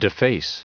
Prononciation du mot deface en anglais (fichier audio)
Prononciation du mot : deface